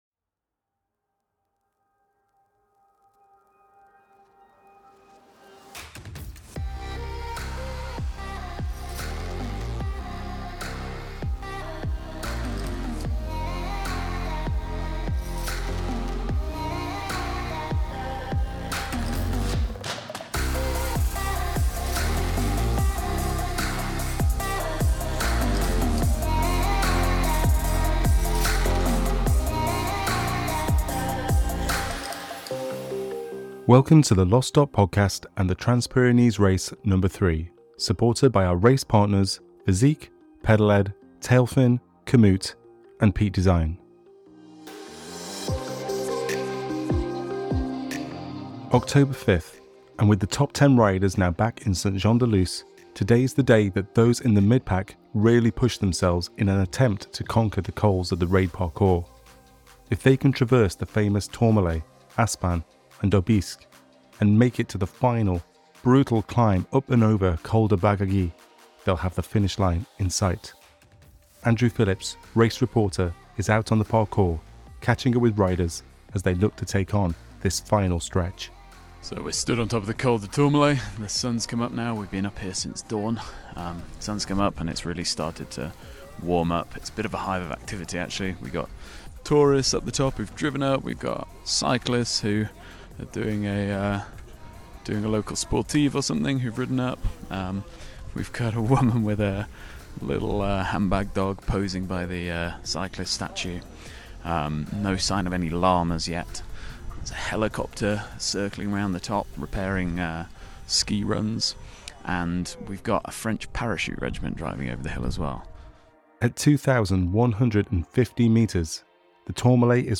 If you’ve enjoyed following along with this year’s Race, you’re not going to want to miss this episode filled with insightful Race interviews. From moments with riders leading up to the Finish, to reflections from those who have already got there, get an in-depth glimpse into TPRNo3.